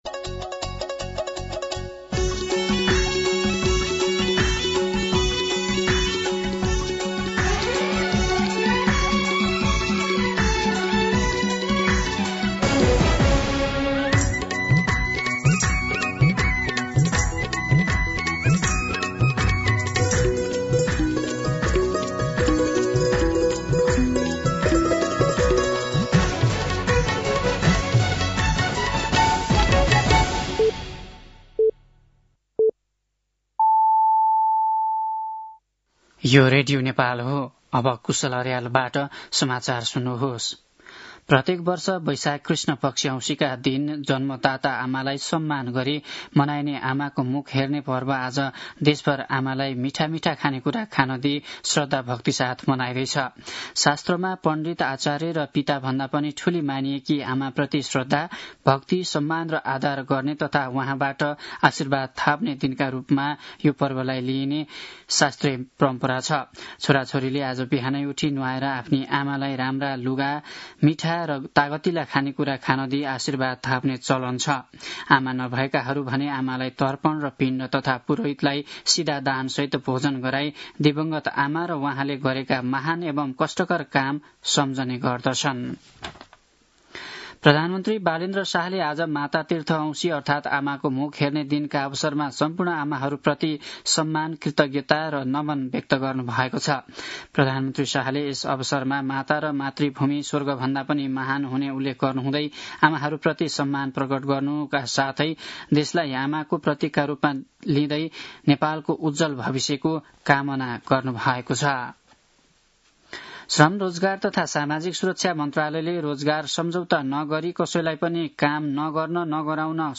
An online outlet of Nepal's national radio broadcaster
दिउँसो ४ बजेको नेपाली समाचार : ४ वैशाख , २०८३